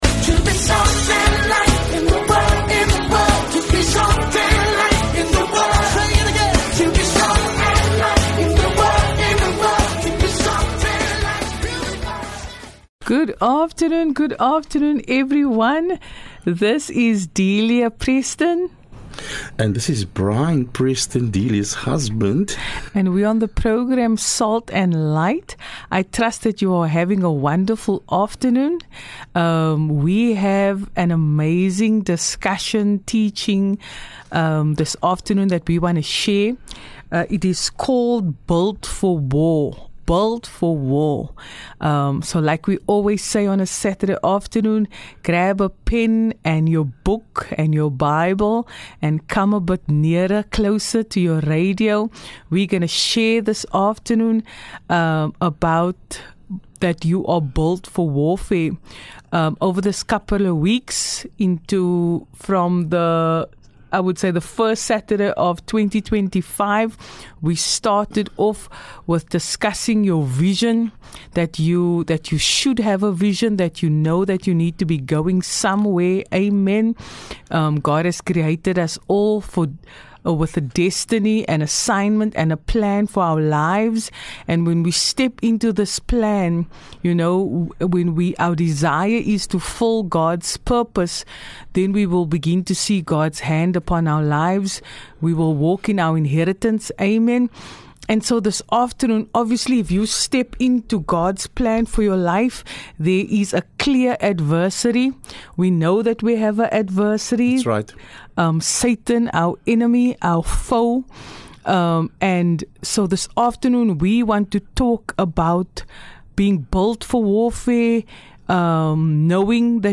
In this inspiring discussion, they dive into the spiritual warfare every believer faces as they pursue God’s purpose for their life. From the story of the Israelites crossing into the Promised Land to David's victory over Goliath, they encourage you to stand firm in your faith and rise above opposition.